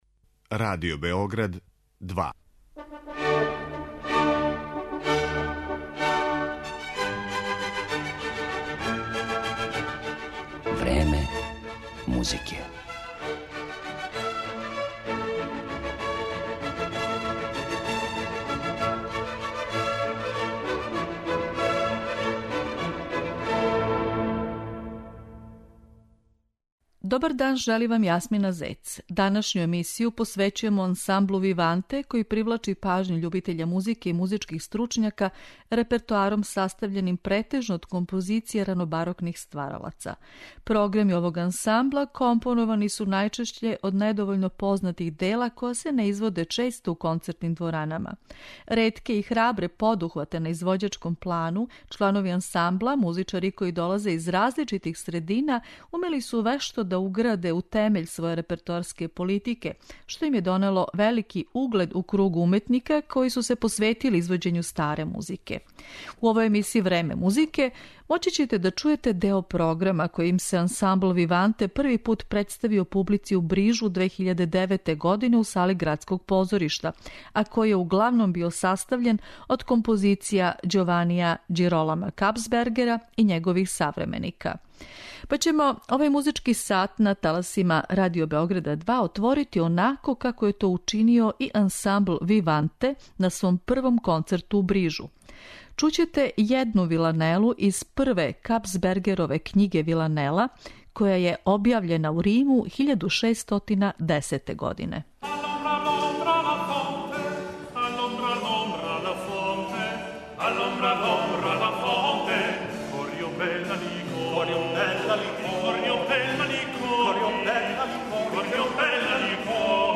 Емитоваћемо композиције Ђованија Ђиролама Капсбергера и његових савременика, у интерпретацији ансамбла 'Виванте', који је специјализован за извођење музике ранобарокних композитора.
Слушаоци ће моћи да чују део програма који је ансaмбл "Виванте" извео на свом првом концерту у Брижу, 2009. године.